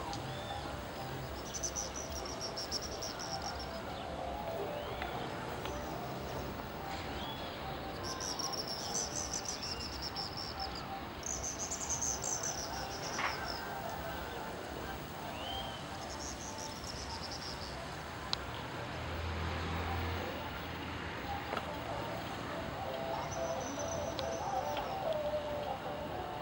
Andean Swift (Aeronautes andecolus)
Province / Department: Catamarca
Location or protected area: Santa María
Condition: Wild
Certainty: Recorded vocal